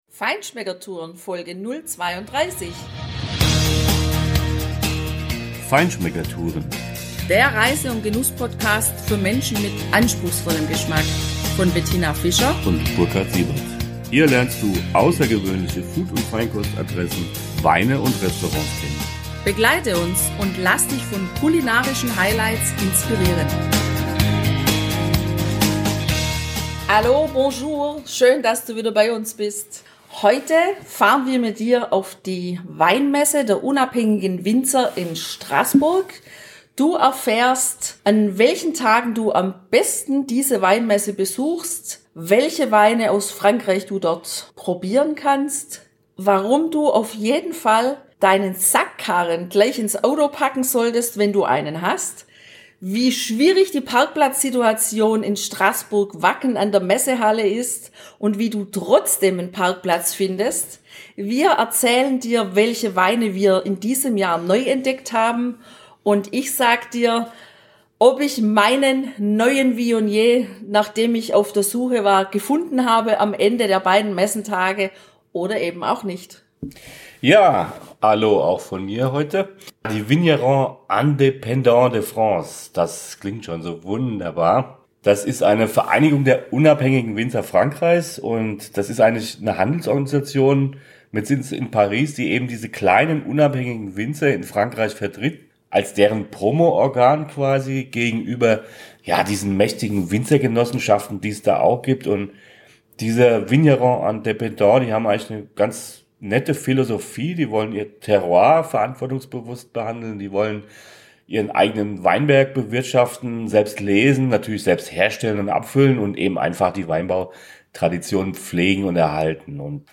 Mit Winzer O-Tönen! 1.
Ein paar davon und Messebesucher kommen selbst zu Wort.